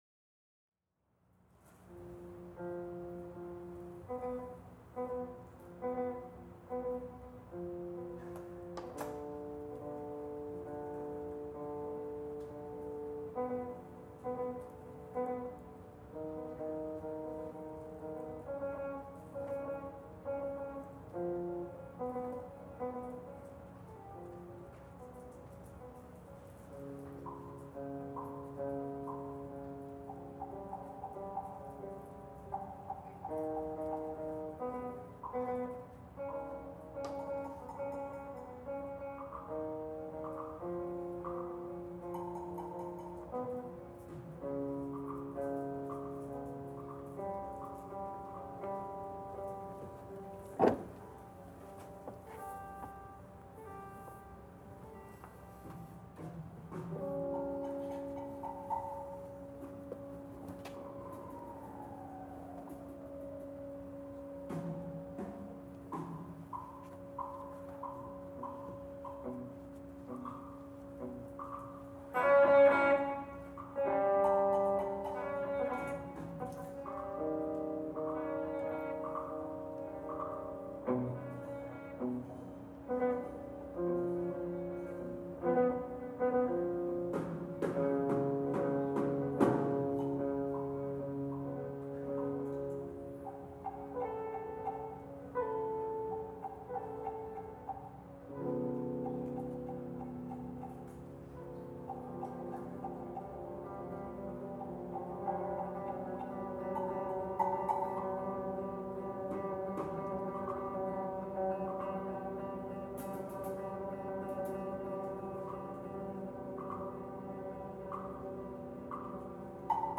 Southeastern Louisiana University